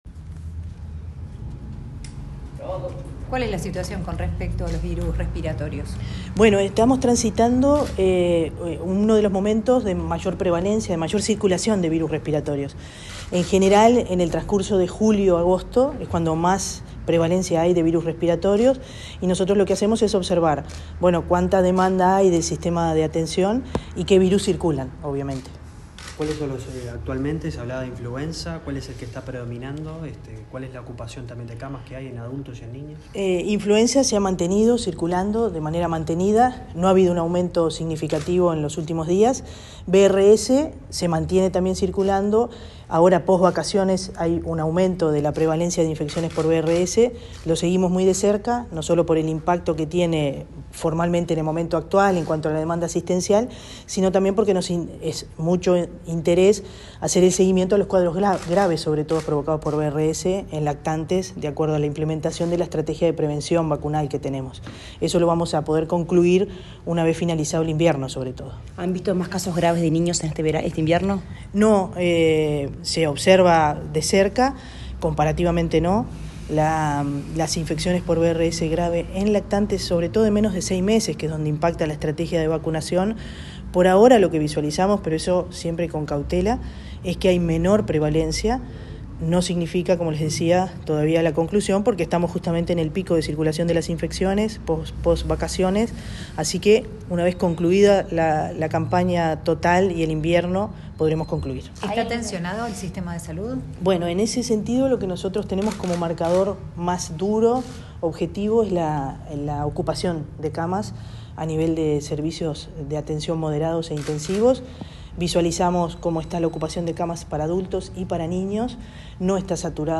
Declaraciones de la directora general de la Salud, Fernanda Nozar
La directora general de la Salud, del Ministerio de Salud Pública, Fernanda Nozar, dialogó con la prensa sobre la situación epidemiológica actual de